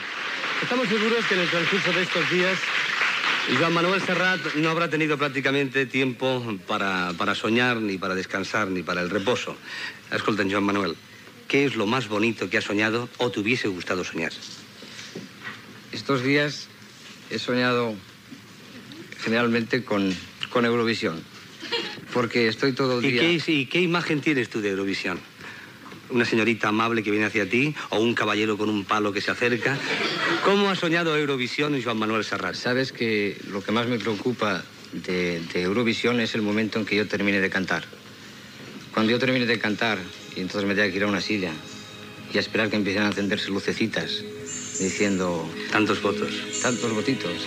Fragment d'una entrevista a Joan Manuel Serrat sobre la seva participació a Eurovisión